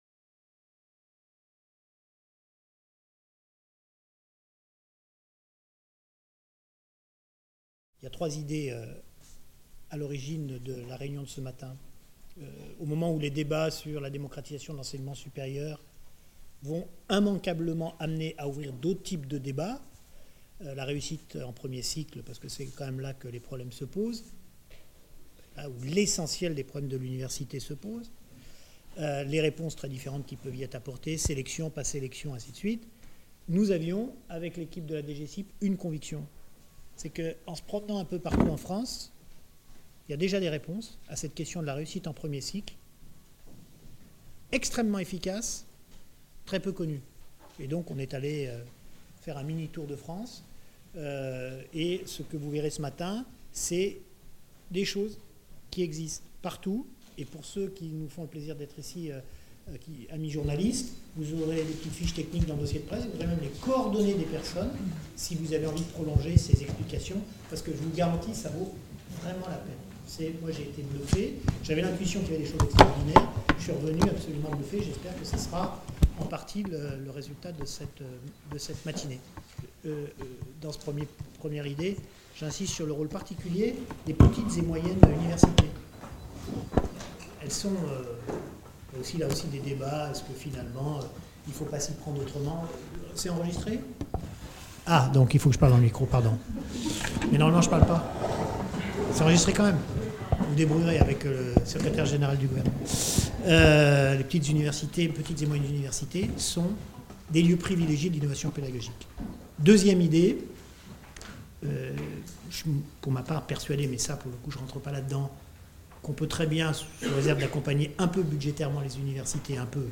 Les initiatives sont présentées par les universités qui les ont mises en place. INTRODUCTION par THIERRY MANDON, secrétaire d’Etat en charge de l’Enseignement supérieur et de la Recherche